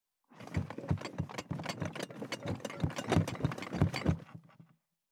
ゴロゴロ,ガタガタ,ドスン,バタン,ズシン,カラカラ,地震,引っ越し,荷物運び,段ボール箱の中身,部署移動,176
効果音荷物運び